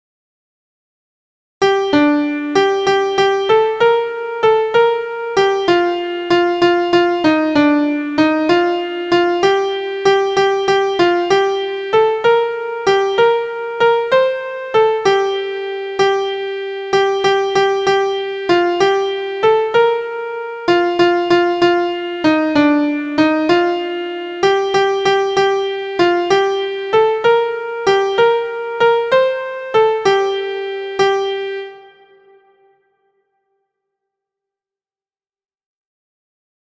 Intermediate syncopation in 6/8 time with the most common
intervals found in a minor tonality (key).
• Origin: Ireland/USA – Chanty – Railroad Work Song – 1864
• Key: A minor (pitched in C Major)
• Time: 6/8
• Form: AB – verse/refrain